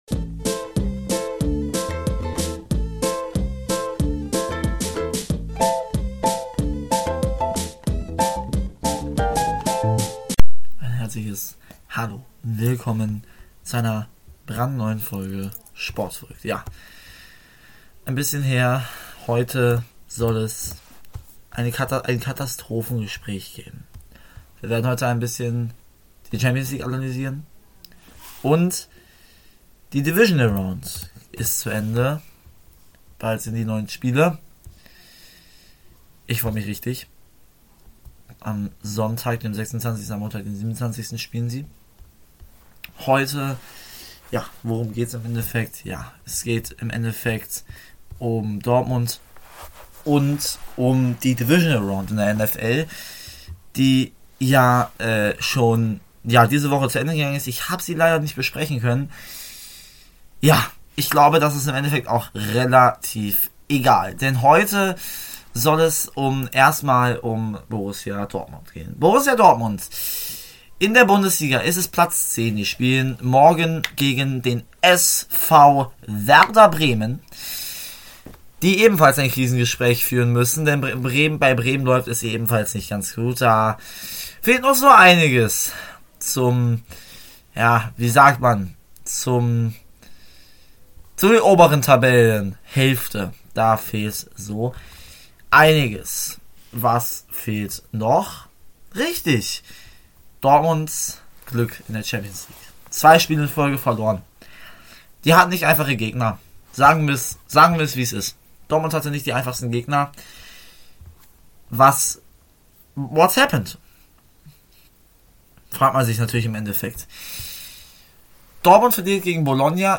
Hallo meine lieben, heute gibt es einen weiteren Dortmunder Krisentalk (mal wieder;)) und ich kläre alles von der Divisionalround.